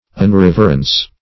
Search Result for " unreverence" : The Collaborative International Dictionary of English v.0.48: Unreverence \Un*rev"er*ence\, n. Absence or lack of reverence; irreverence.